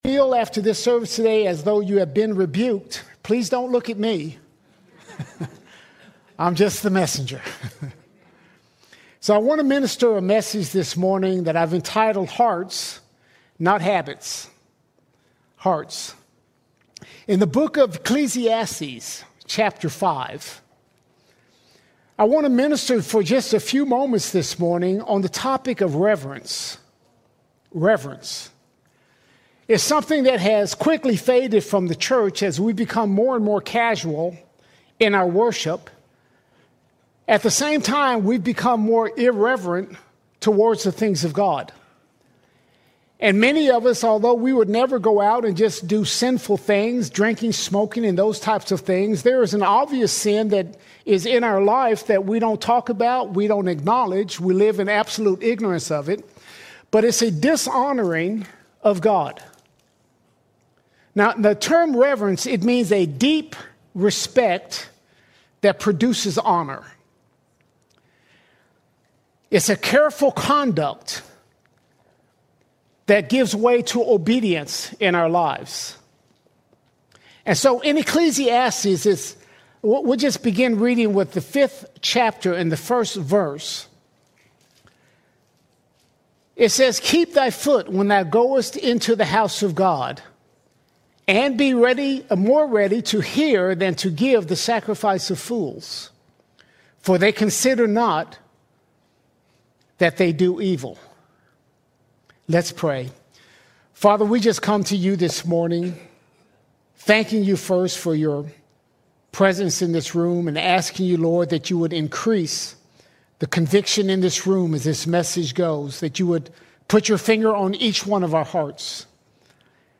29 December 2025 Series: Sunday Sermons All Sermons Hearts, Not Habits Hearts, Not Habits God is calling you and I to move beyond routine faith.